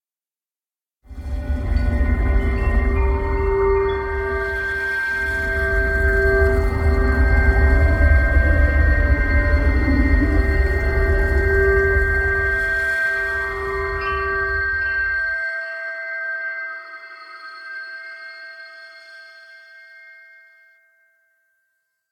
Tinnitus_06.ogg